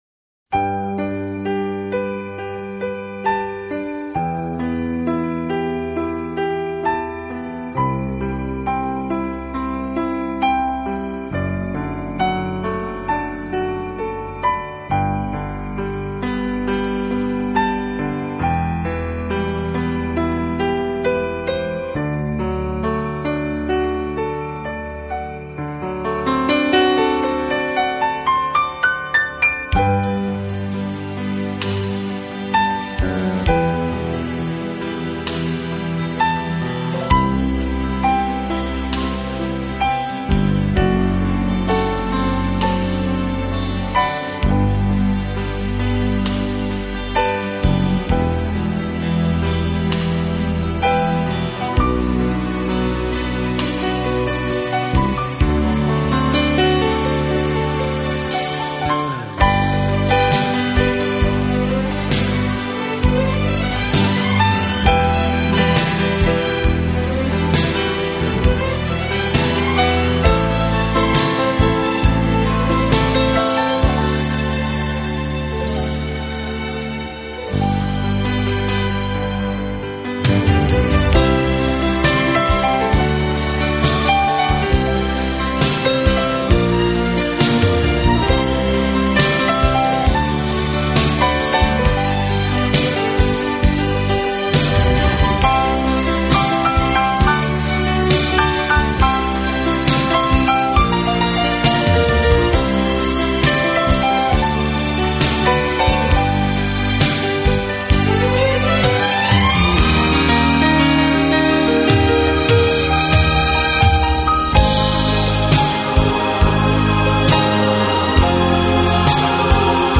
这是一套非常经典的老曲目经过改编用钢琴重新演绎的系列专辑。
钢琴演奏版，更能烘托出复古情怀，欧美钢琴大师深具质感的演奏功力，弹指
本套CD全部钢琴演奏，